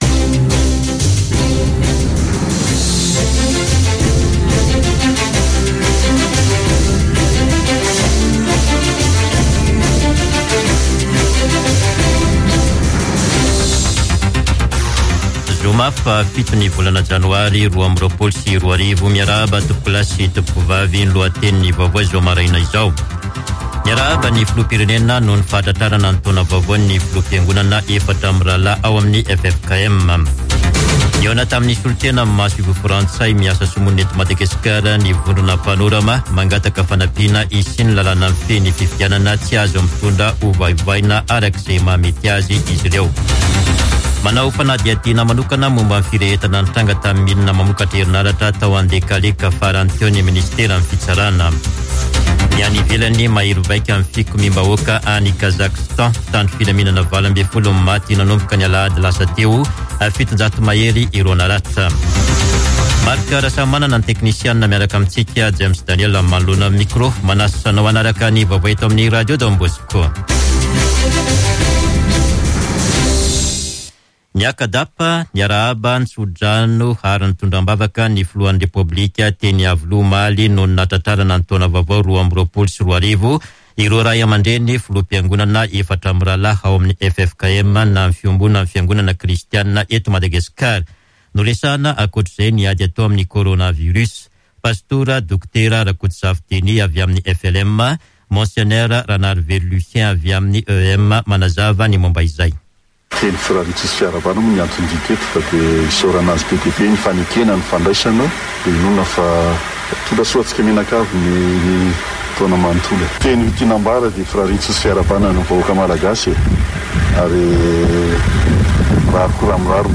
[Vaovao maraina] Zoma 7 janoary 2022